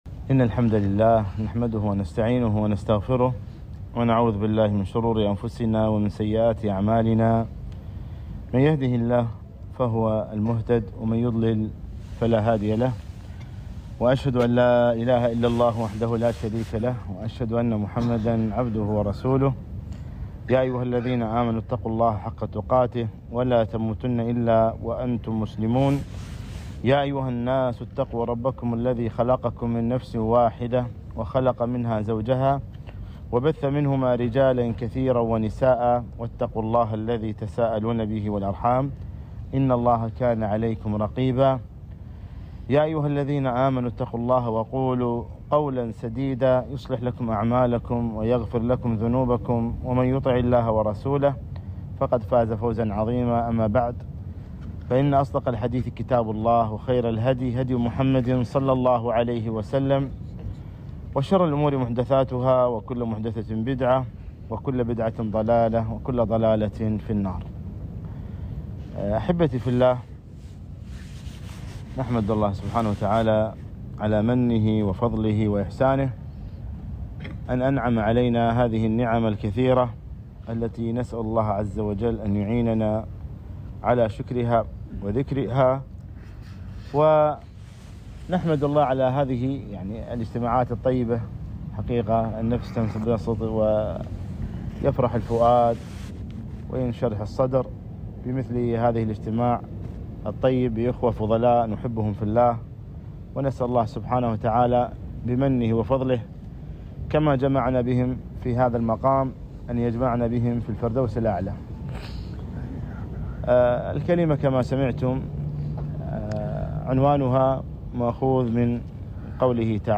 محاضرة - وأن مردَّنا إلى الله